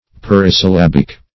Search Result for " parisyllabic" : The Collaborative International Dictionary of English v.0.48: Parisyllabic \Par`i*syl*lab"ic\, Parisyllabical \Par`i*syl*lab"ic*al\, a. [Pari- + syllabic, -ical: cf. F. parisyllabique.] Having the same number of syllables in all its inflections.